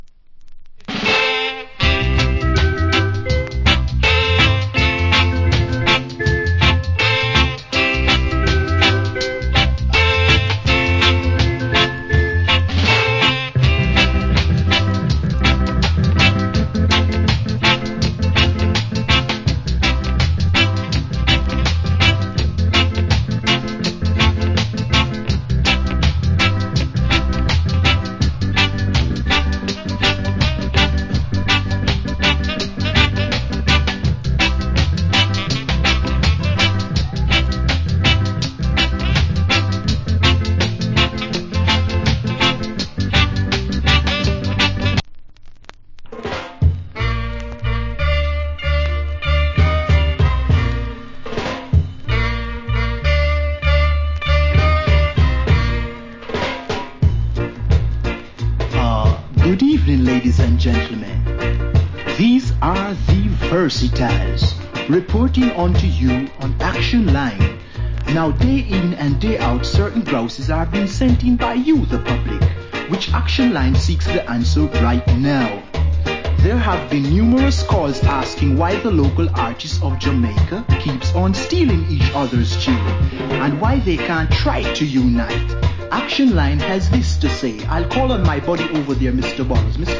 Wicked Rock Steady Inst.